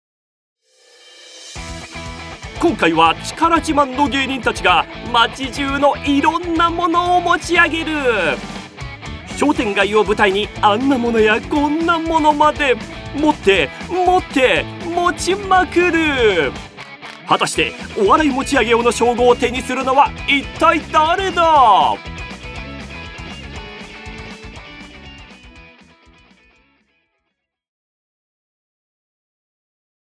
ボイスサンプル
バラエティ番